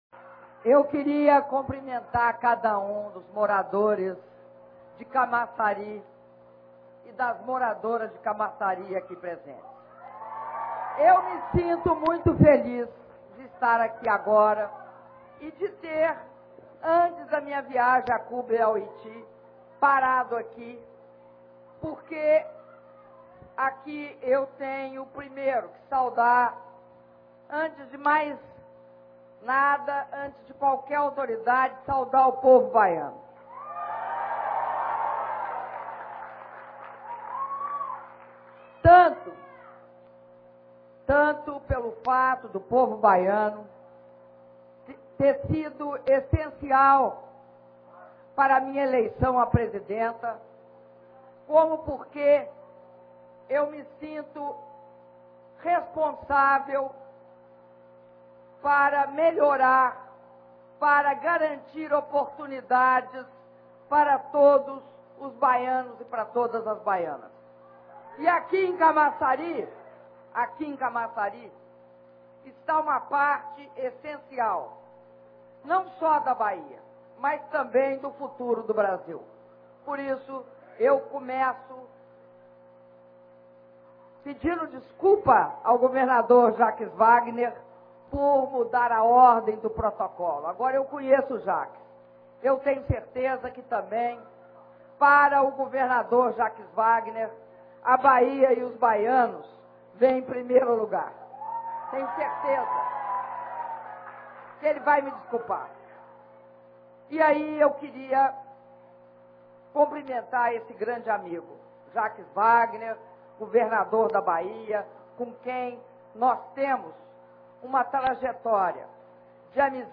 Discurso da Presidenta da República, Dilma Rousseff, na cerimônia de emissão da ordem de serviço de início das obras de Urbanização Integrada da Bacia do Rio Camaçari - Camaçari/BA